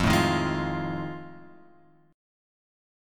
F 7th Sharp 9th